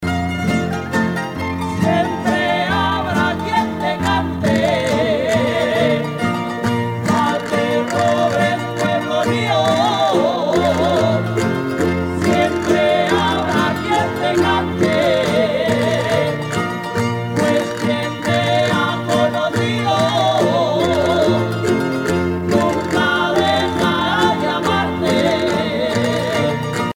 danse : jota (Espagne)
Grupo folclorico
Pièce musicale éditée